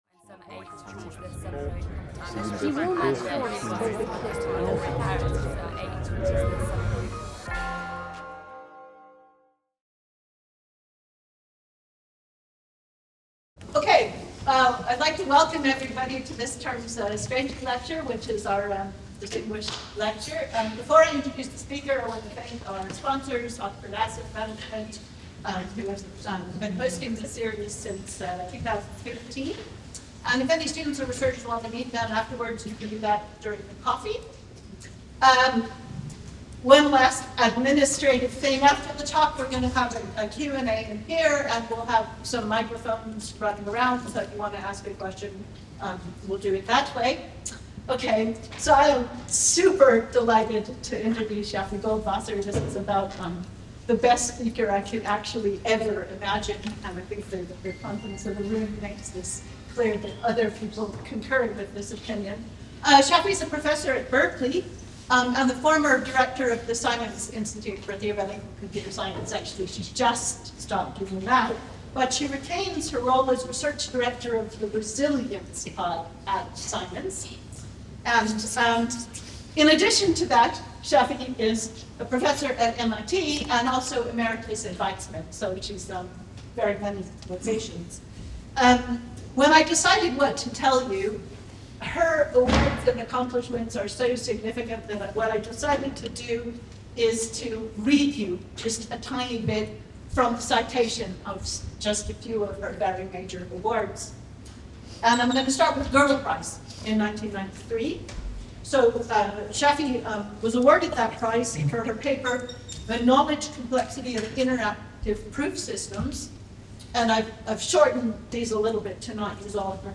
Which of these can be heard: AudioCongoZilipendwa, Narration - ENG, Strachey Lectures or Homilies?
Strachey Lectures